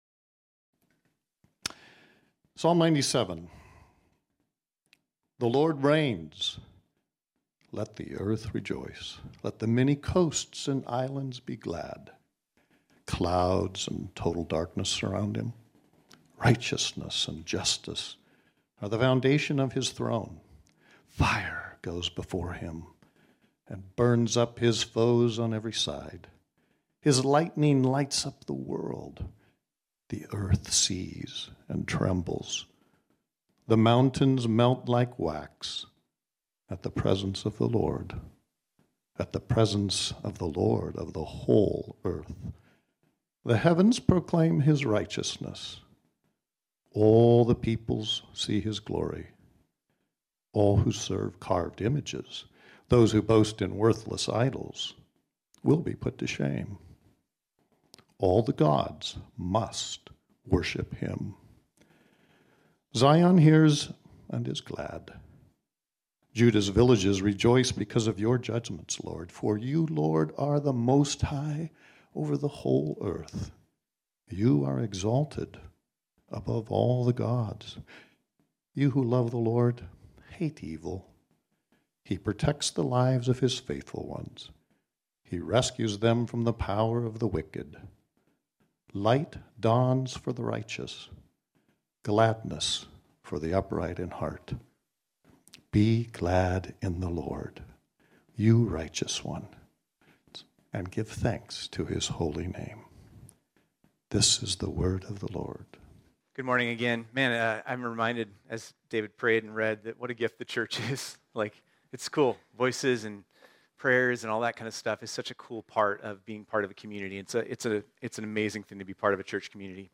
This sermon was originally preached on Sunday, December 12, 2021.